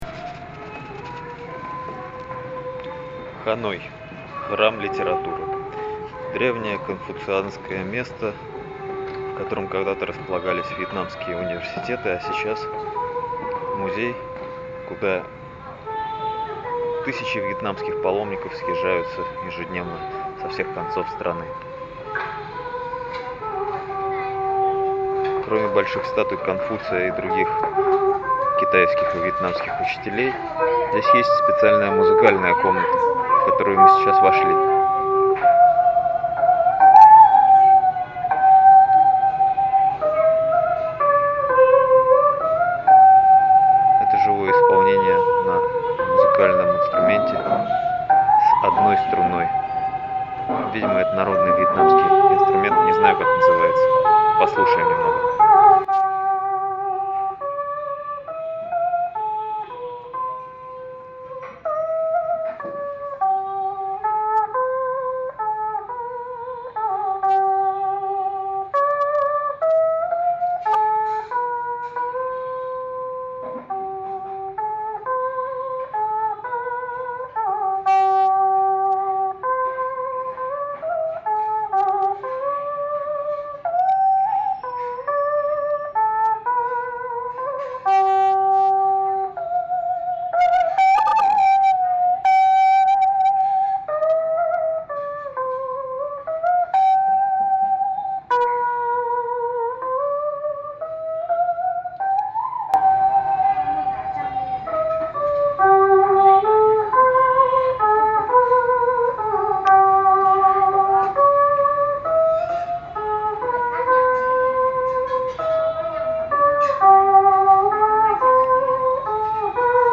традиционный вьетнамский музыкальный инструмент называется Данг бо, его можно услышать выше